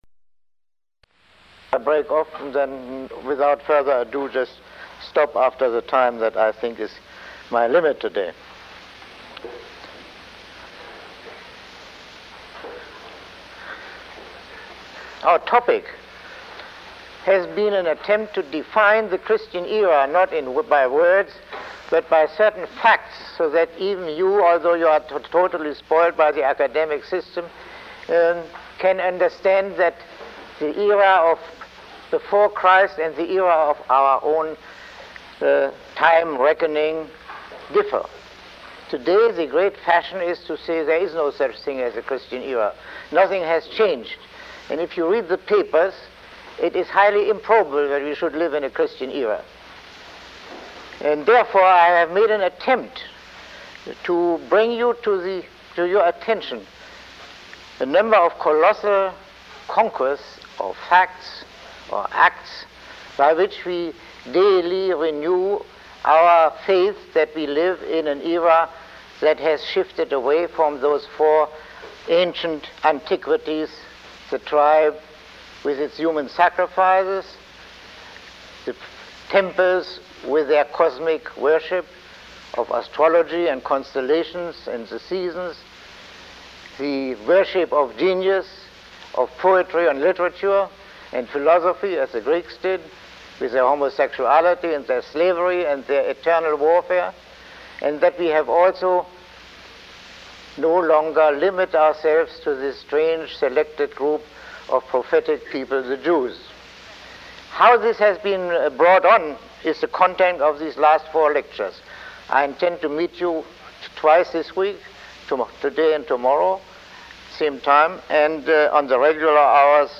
Lecture 17